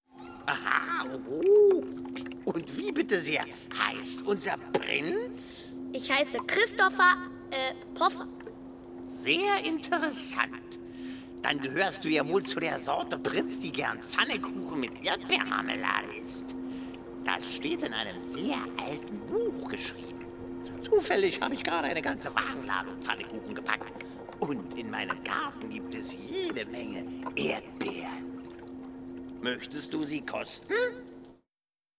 Stimmproben
Hörspiel - Das Herz von Jayne Mansfield